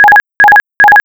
calvary-charge-once.wav